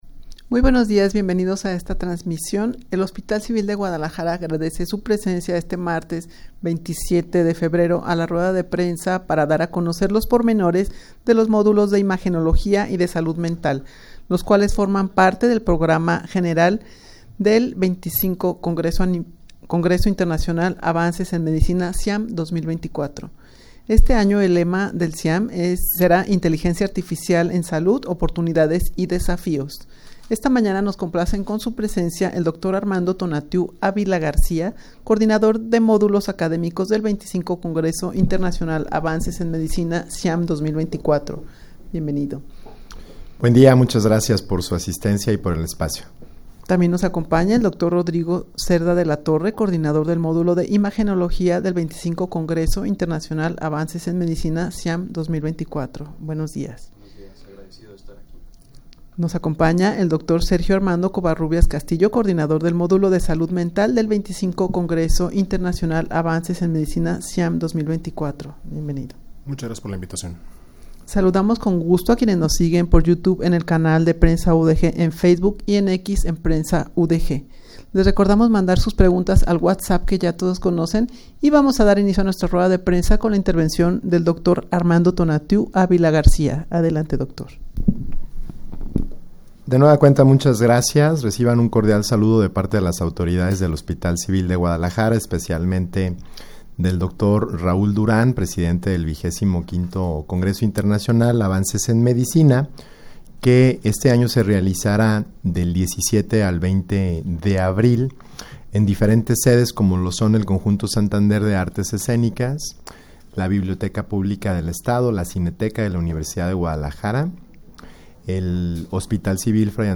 Audio de la Rueda de Prensa
rueda-de-prensa-para-dar-a-conocer-los-pormenores-de-los-modulos-de-imagenologia-y-de-salud-mental.mp3